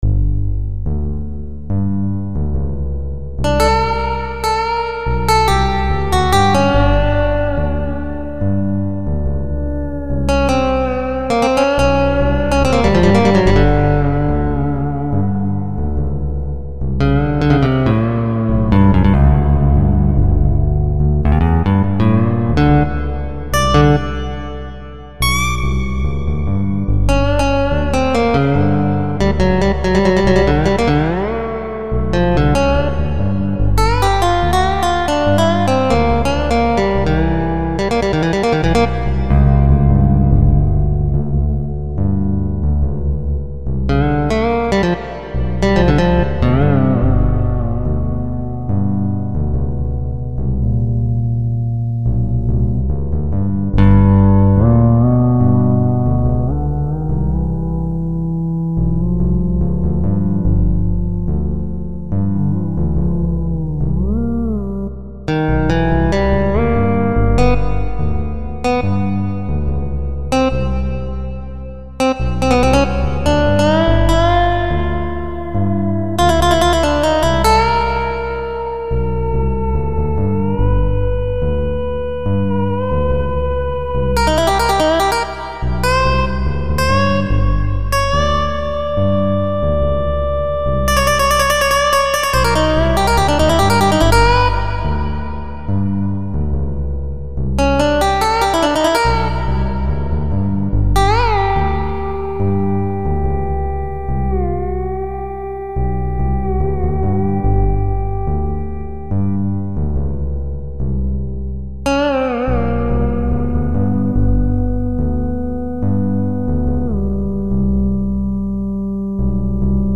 These were all written in music making software.
seeing how long I can drag out the notes.